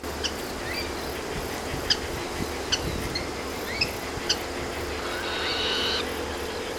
朝の内、東観察舎ではクイナの鳴き声が盛んで３パターンの声が一度に聞けた。（キッキッと共にビュービュー、グギイー、グギーと賑やかな声）おそらく複数のクイナがいたのだろう。
本日の録音データから３声が入った部分を抜き出してみた～